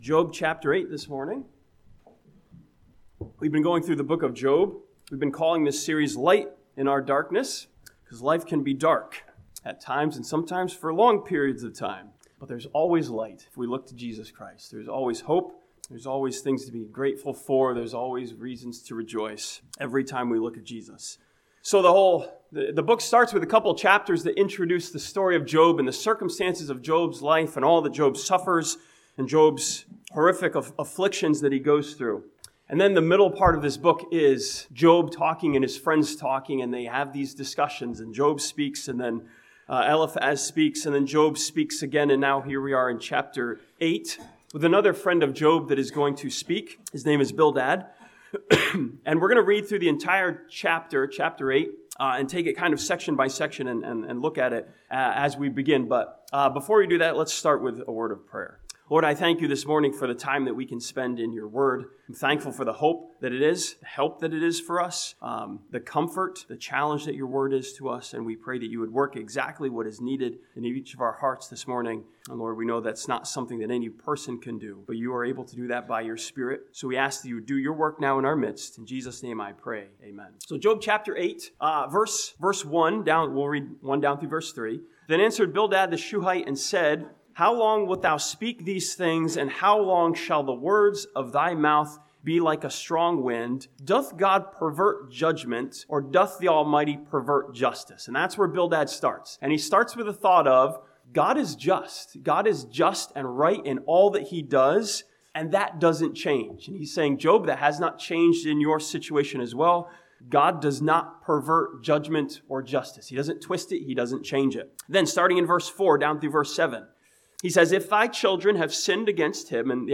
This sermon from Job chapter 8 challenges us in the darkness of chaos to find the light of God's order.